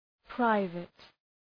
{‘praıvıt}